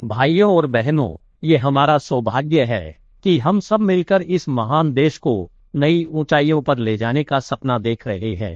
11mlabs/indri-0.1-124m-tts at main